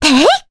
Cassandra-Vox_Attack3_jp.wav